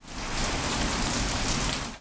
rain_1.ogg